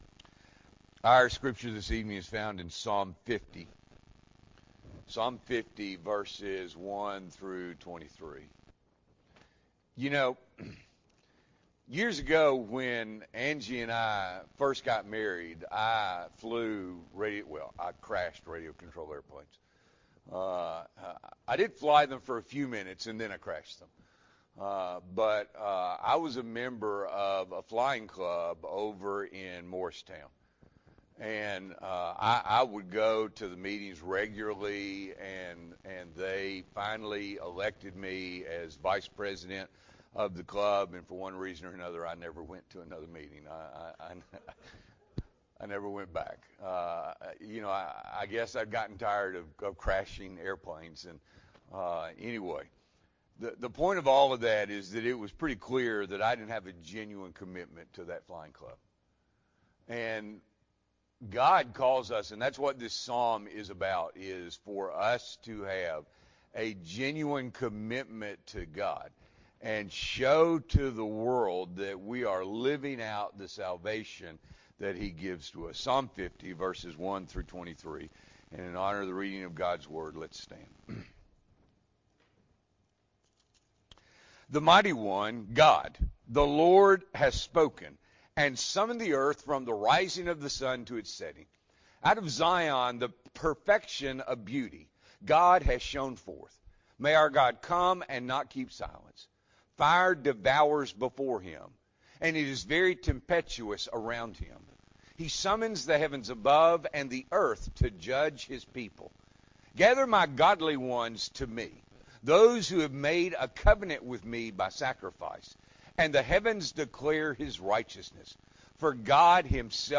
October 8, 2023 – Evening Worship